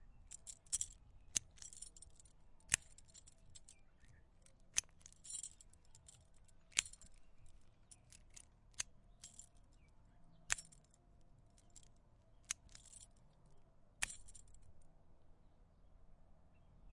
SFX Library 2018 » Padlock
描述：This was recorded with an H6 Zoom recorder at home. I had a small padlock and thought it would serve well as something unlocking or locking with the jingling of the keys.
标签： unlocking locking clink clinking key unlock padlock metallic padlock metal jingling lock keys OWI
声道立体声